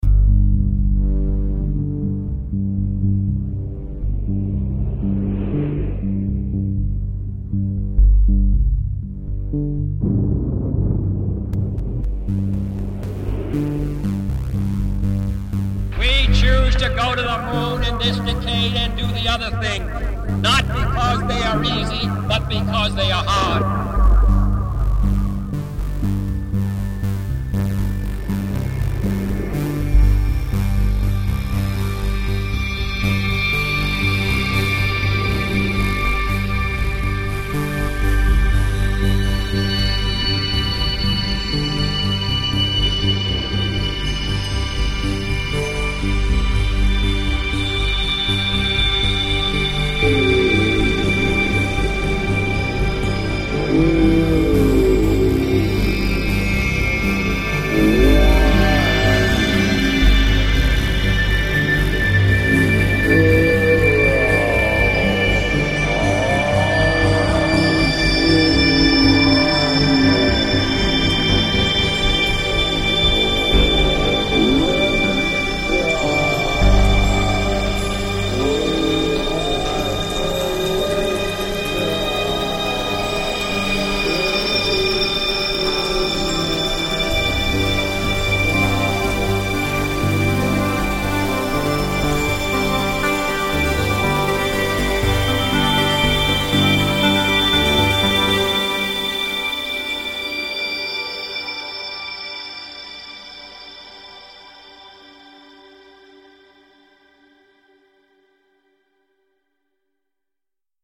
JFK speech reimagined